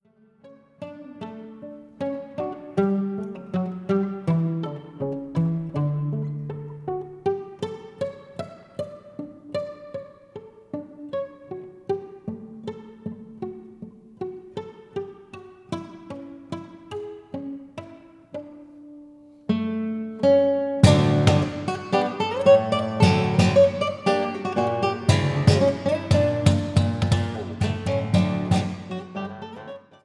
輝く太陽、きらめく海、そして平和への祈り・・・・2台のギターが描く夏の風景。
彼らの魅力は、テクニックを越えて、2本のギターが一体となって瑞々しい響きを醸し出しているところにある。
アルバムのトーンは、まず第一に「楽しさ」であり、時に加えられるボサノヴァ風なリズムなど、ポップな趣がそれに輪をかける。
高いテクニックに支えられたアグレッシヴなアンサンブルが爽快だ。